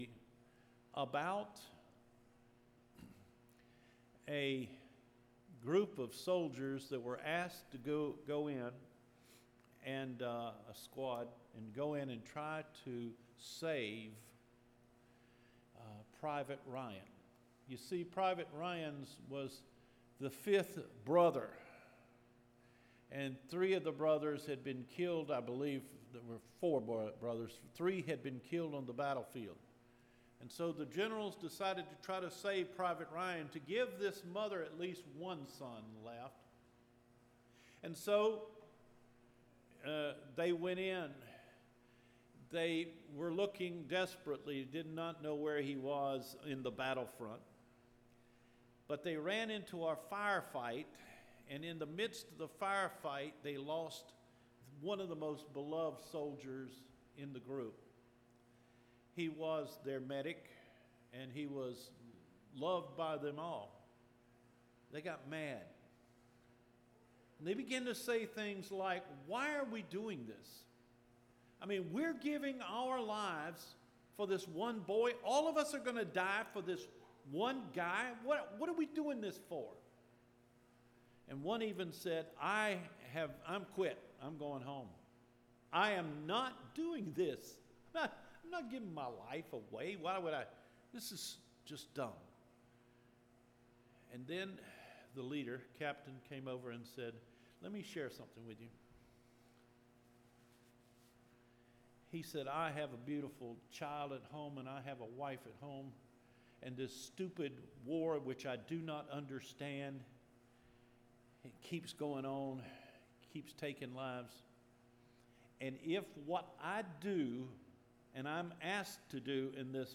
Recorded Sermons